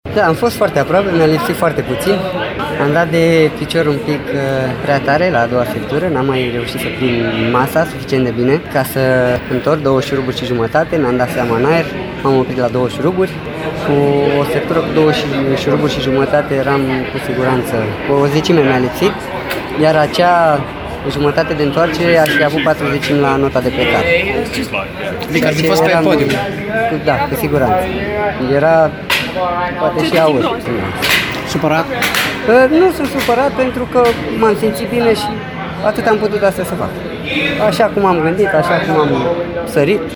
}ntr-un dialog